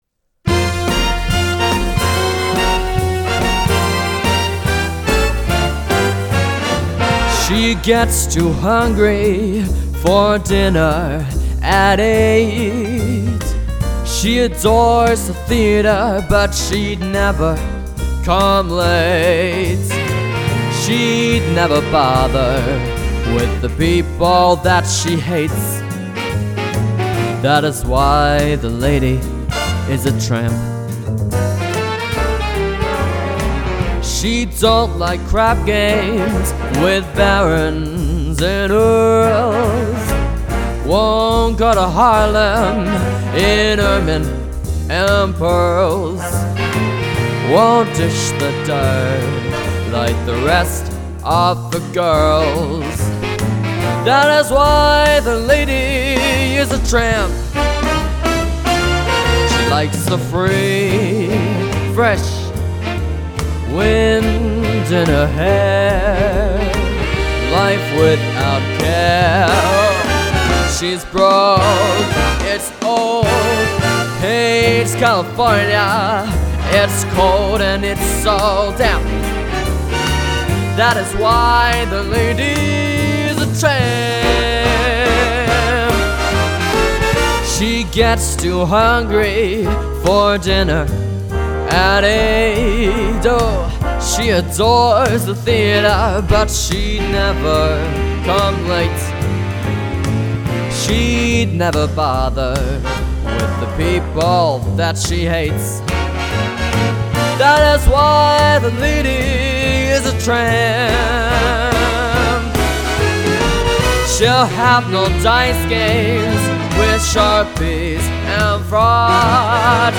A Musician, Singer/Pianist with Talent Beyond His Years